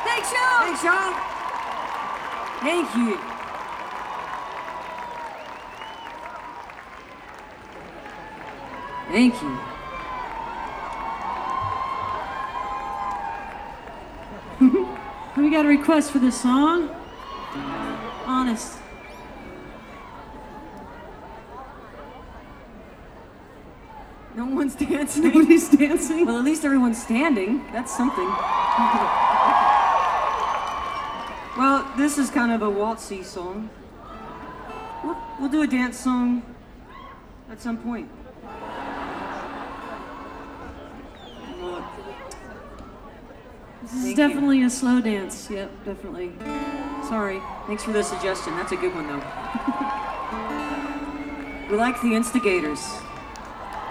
11. talking with the crowd (0:53)